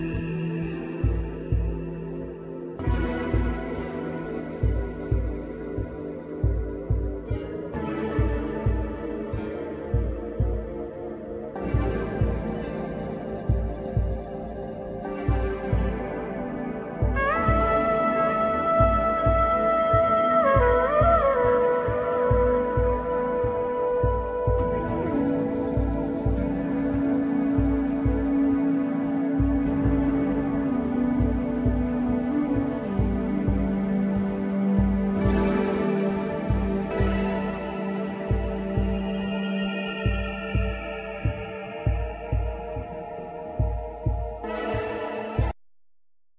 Trumpet,Keyboards
Percussion
Balafon
Djembe
Flute,Percussion,Vocal
Tama Soucou(Talking Drums)
Bara drum